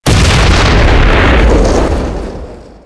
1 channel
DefaultBuildingExplodeb.wav